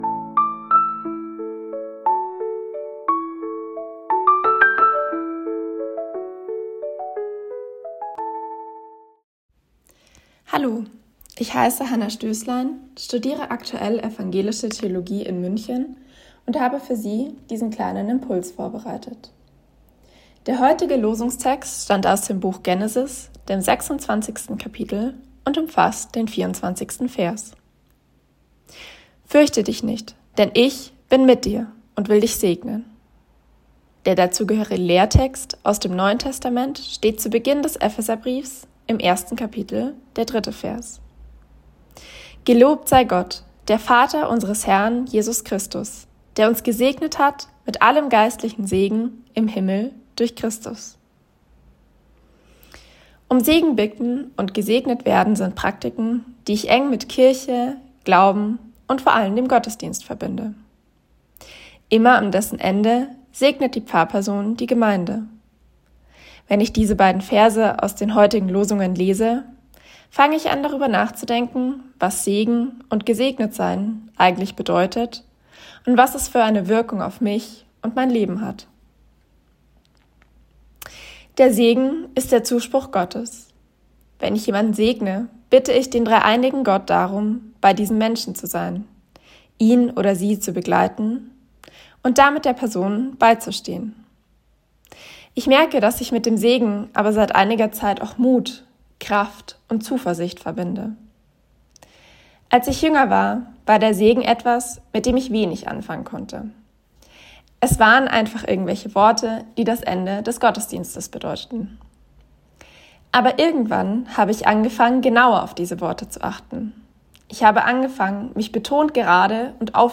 Losungsandacht für Donnerstag, 16.10.2025
Text und Sprecherin